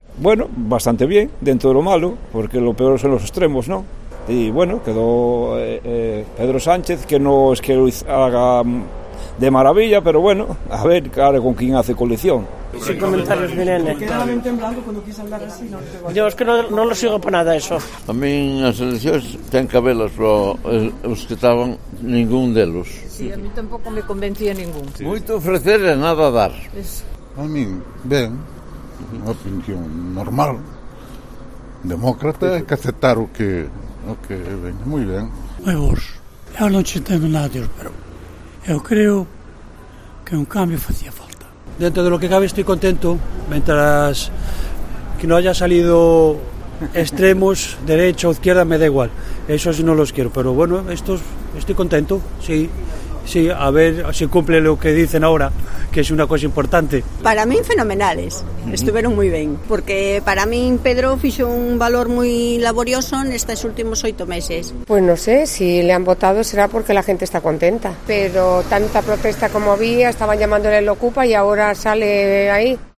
Los vecinos de A Mariña opinan sobre la victoria de Pedro Sánchez